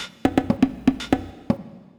120_HH+perc_1.wav